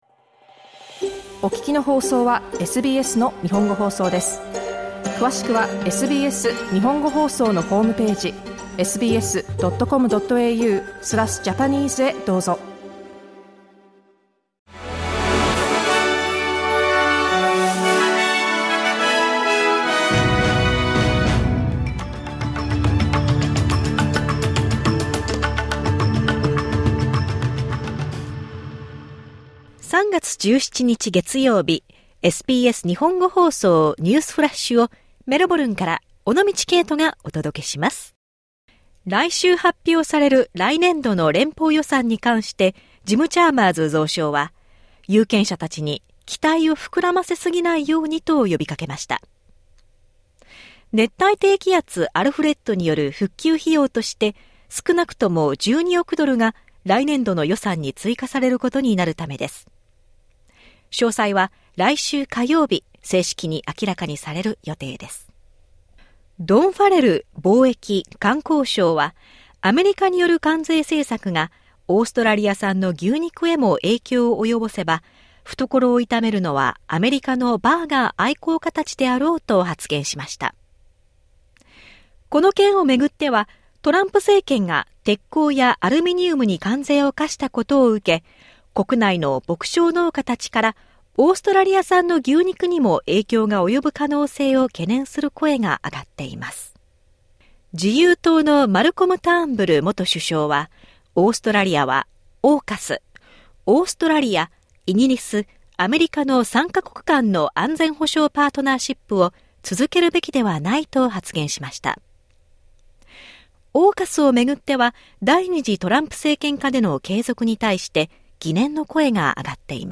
SBS日本語放送ニュースフラッシュ 3月17日 月曜日